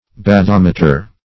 Bathometer \Ba*thom"e*ter\, n. [Gr. baqo`s depth + -meter.]